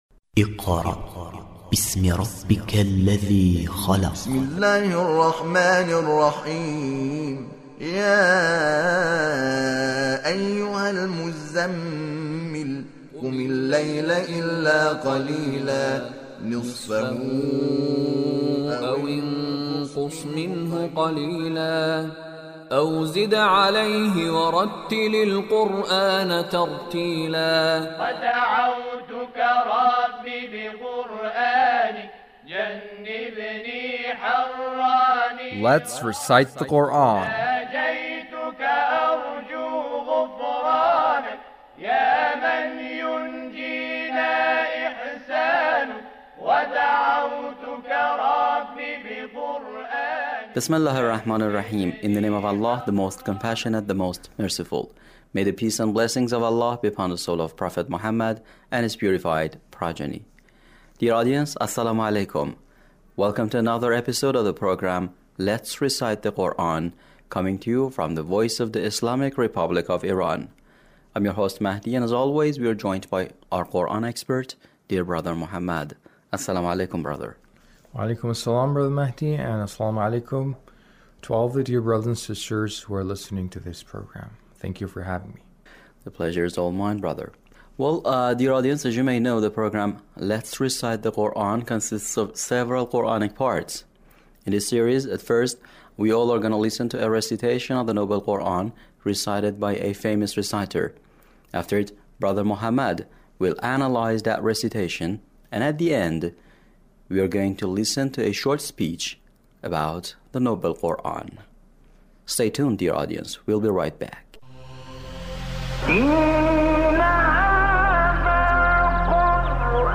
Abul Ainain Shuaisha recitation